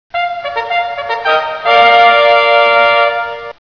fanfar.wav